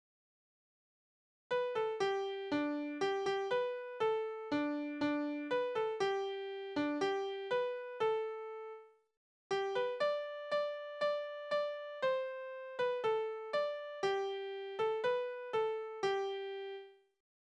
Balladen: Vertröstung mit der Hochzeit auf den Nimmerleinstag
Tonart: G-Dur
Taktart: 4/4
Tonumfang: Oktave
Besetzung: vokal